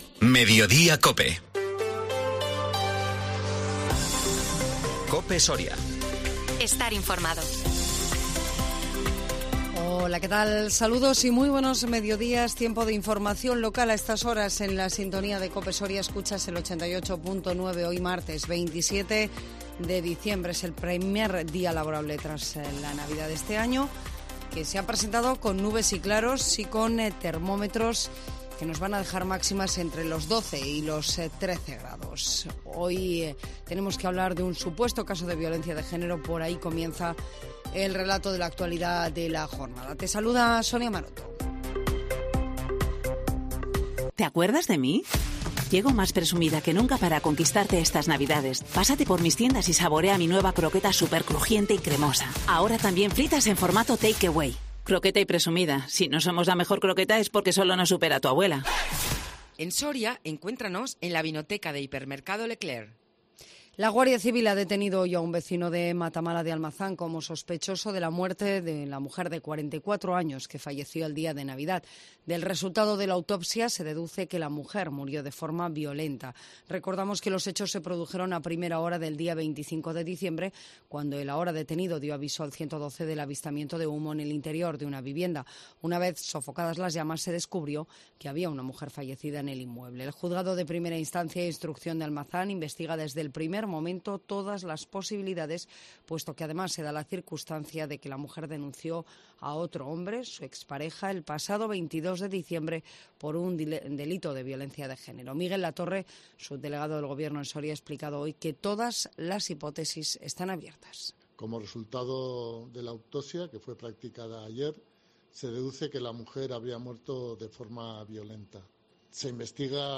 INFORMATIVO MEDIODÍA COPE SORIA 27 DICIEMBRE 2022